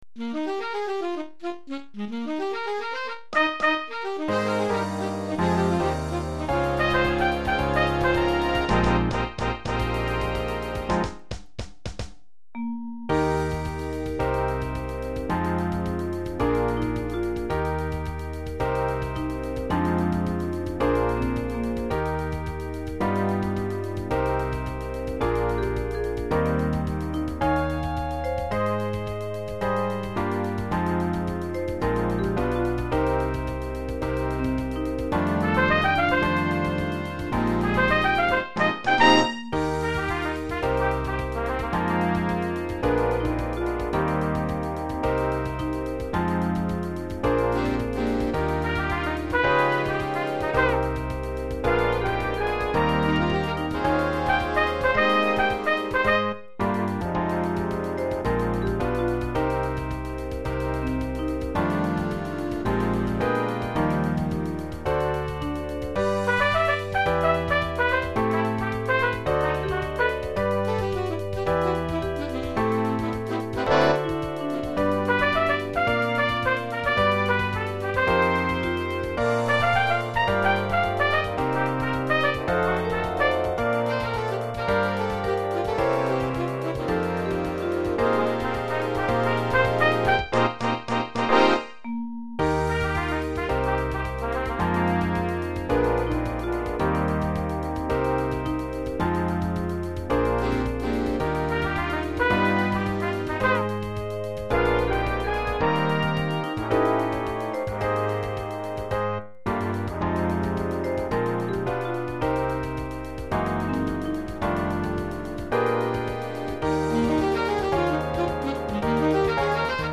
Voix et Big Band